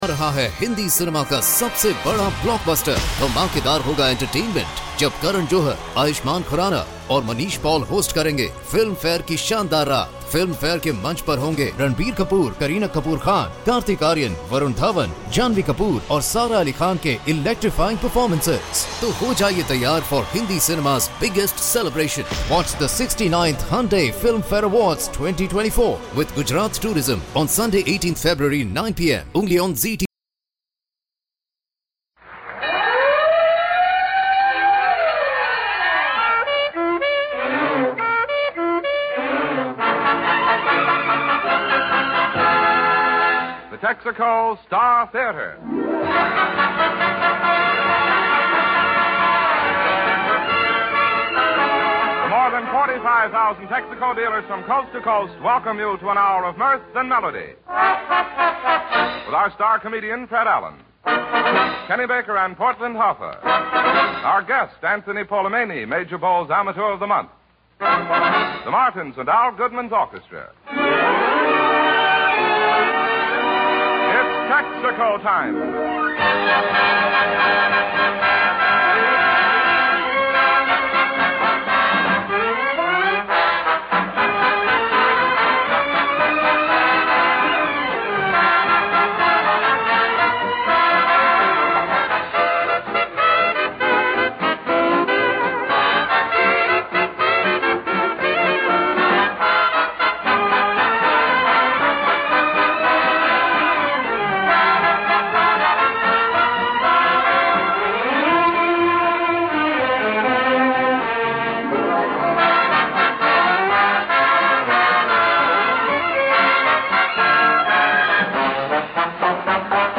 OTR Radio Christmas Shows Comedy - Drama - Variety.